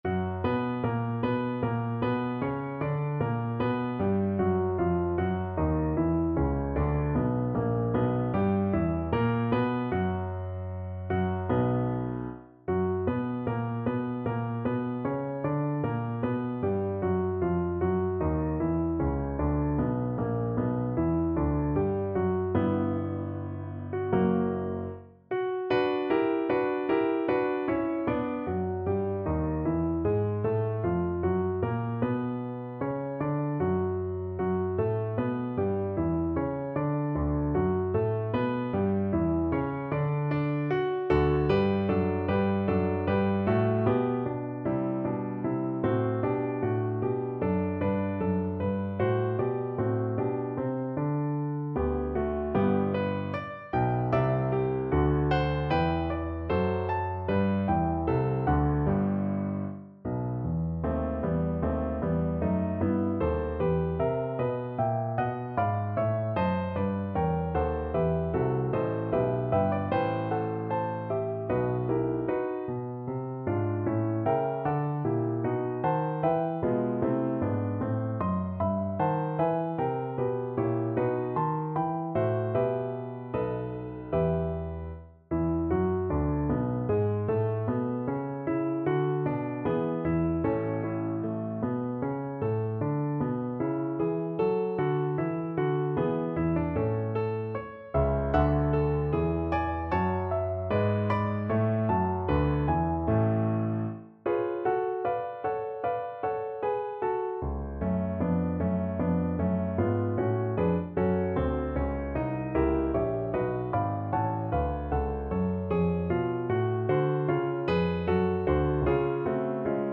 4/4 (View more 4/4 Music)
Larghetto (=76)
Classical (View more Classical Trombone Music)